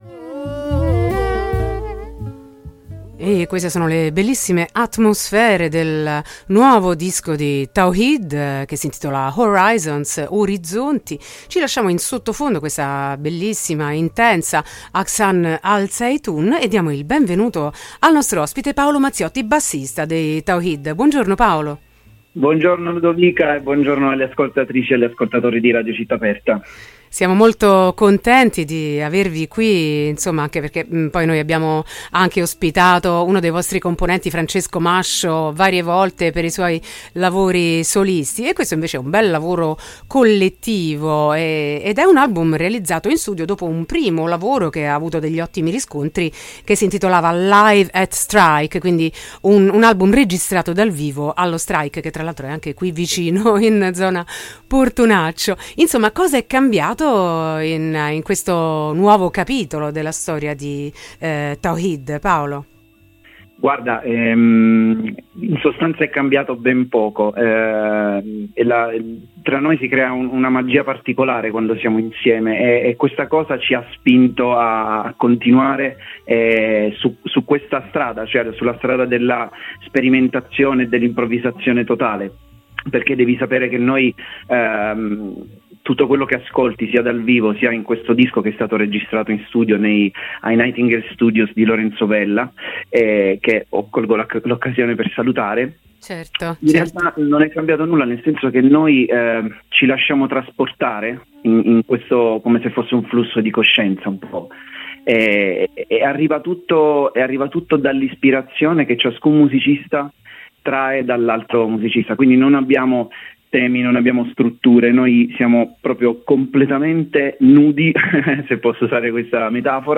intervista-tawhid.mp3